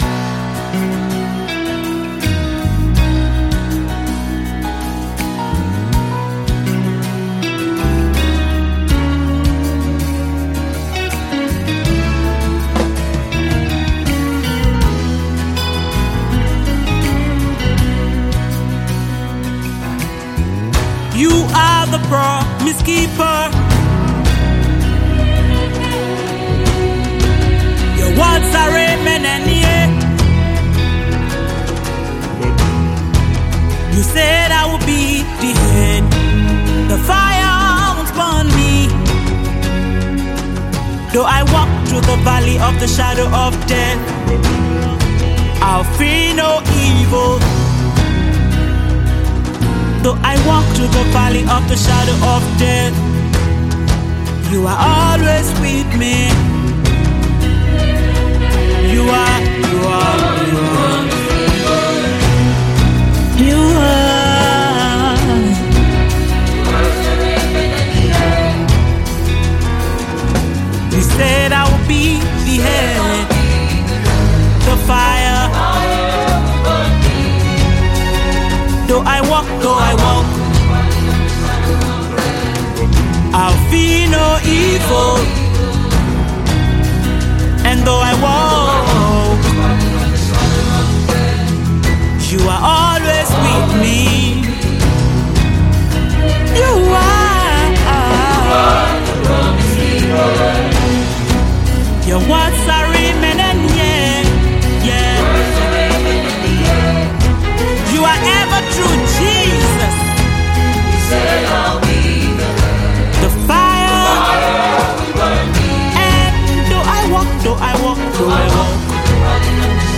Prolific Gospel  songwriter and worshiper